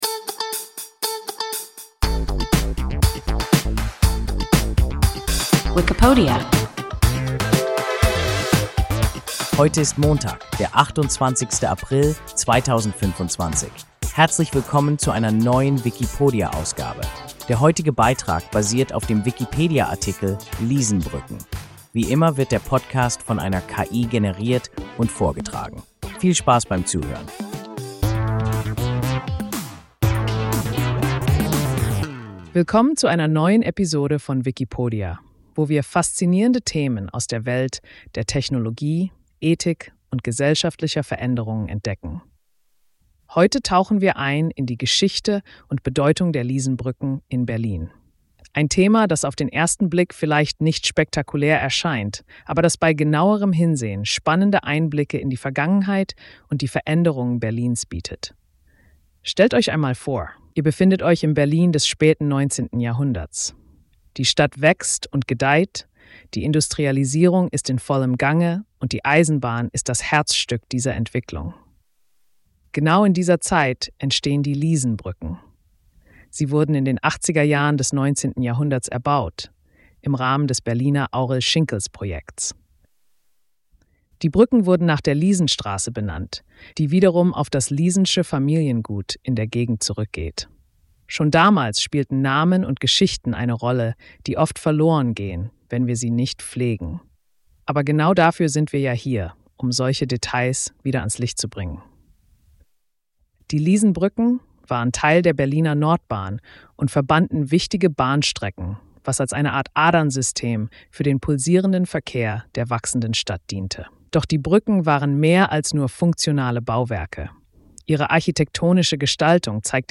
Liesenbrücken – WIKIPODIA – ein KI Podcast